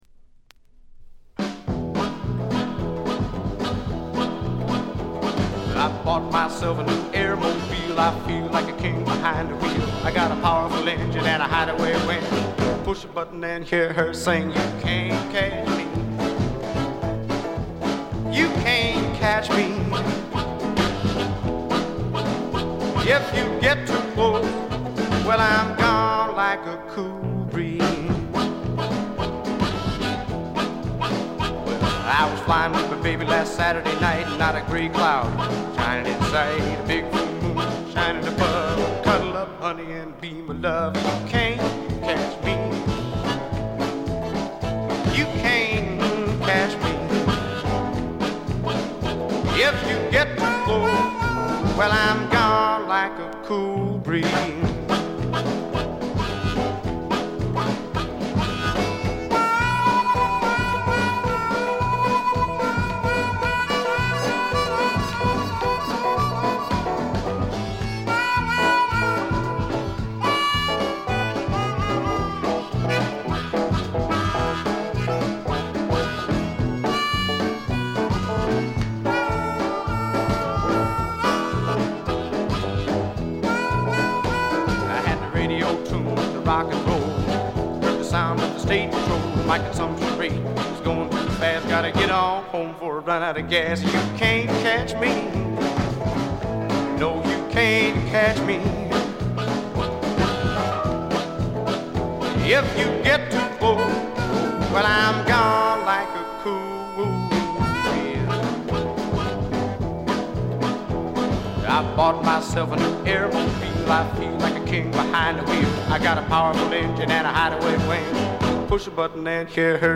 デモと言ってもブルース、R&B色が色濃い素晴らしい演奏を聴かせます。
試聴曲は現品からの取り込み音源です。